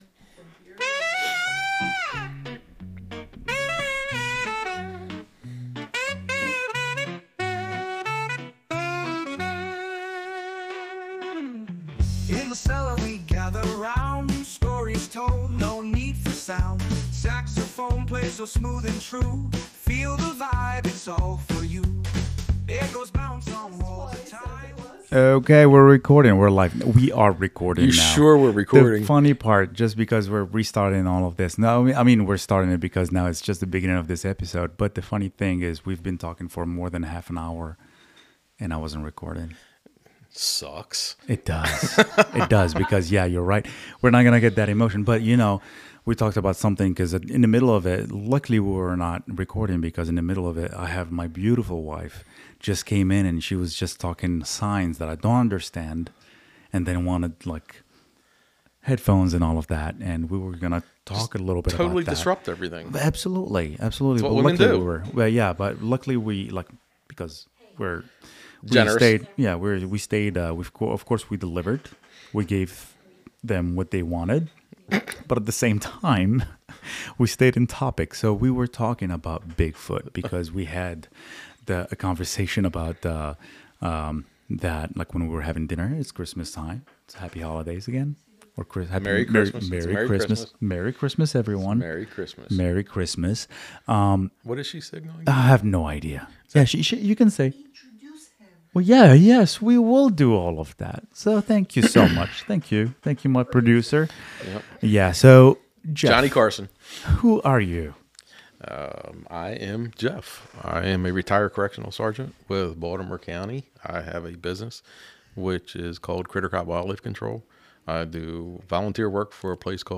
Whether you’re a fan of history, outdoor pursuits, or mythical creatures, this episode has something for everyone. Join us for a great conversation that blends craftsmanship, storytelling, and a touch of the unknown.